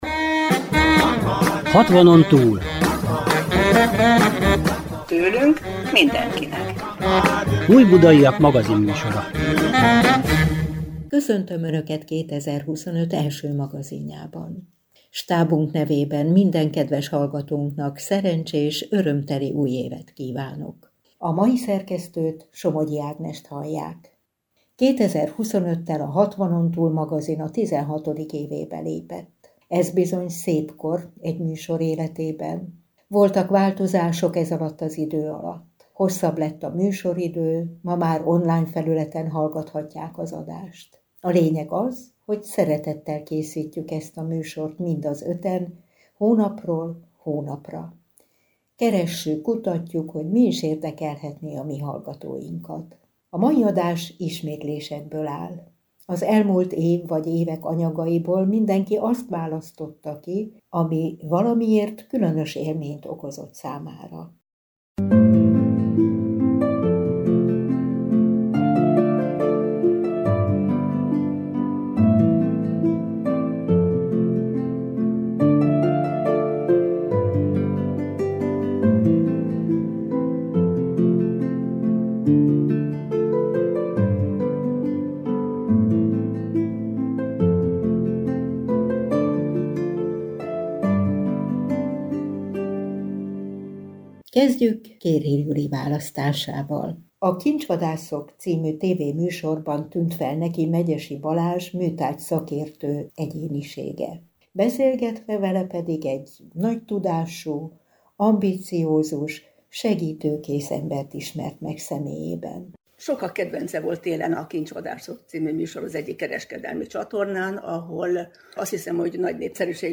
Magazinműsor a Civil Rádióban